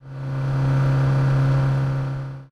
highway / oldcar / tovertake9.ogg